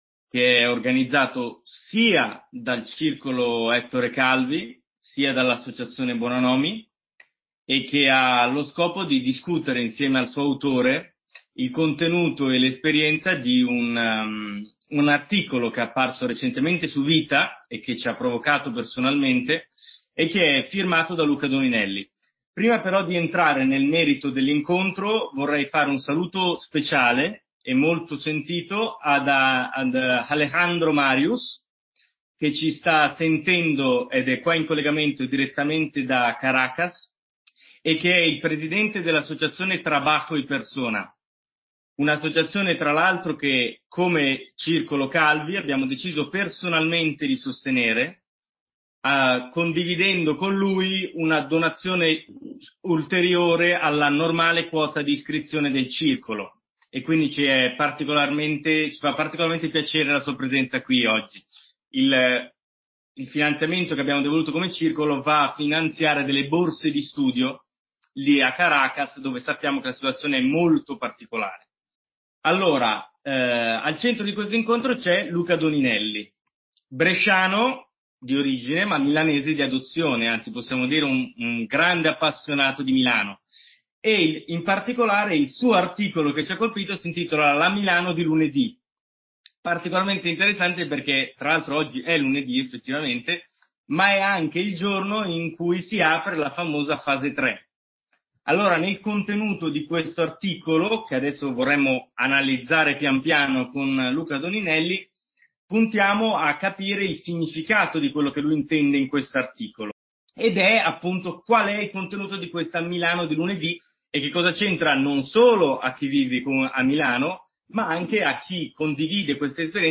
(*) l’incontro si è svolto in modalità videoconferenza: abbiamo avuto cura di eliminare le imperfezioni o le interruzioni che possono essersi verificati nel corso della registrazione a causa della perdita di connessione. Vogliate perdonare eventuali difetti ancora presenti.